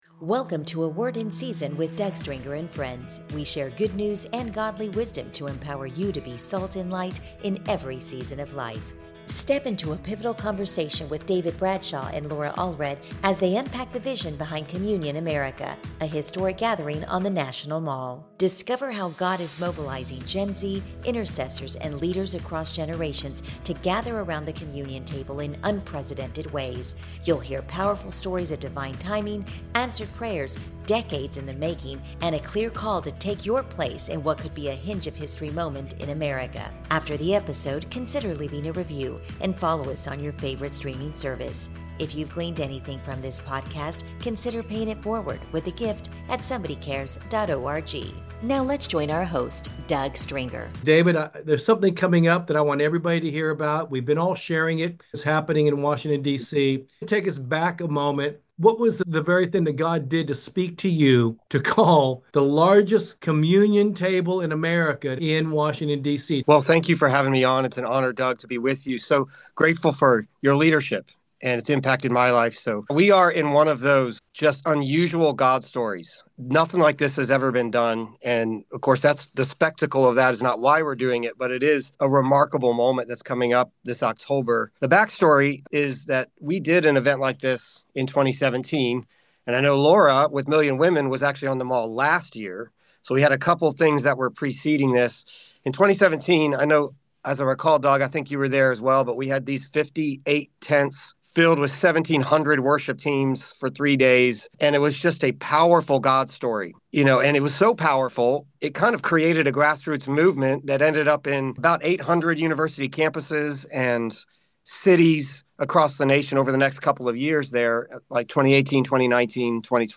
Step into a pivotal conversation